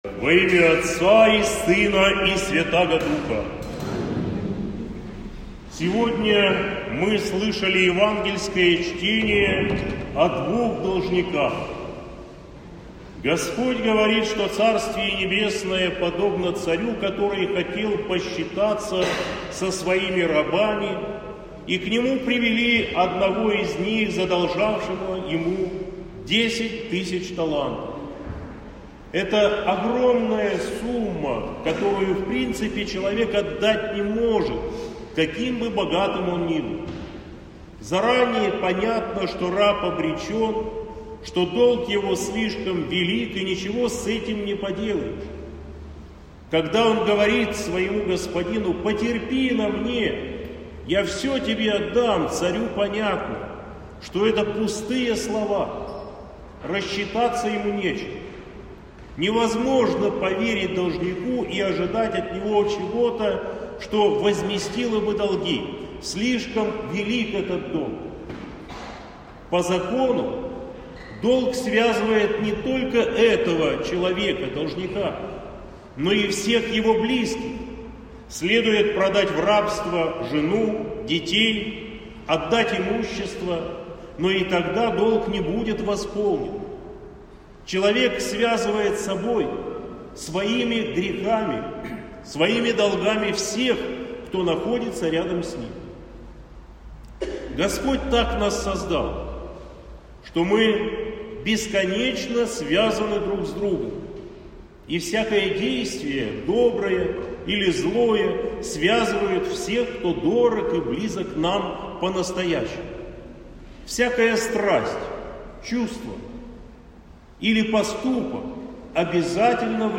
Аудиозаписи проповедей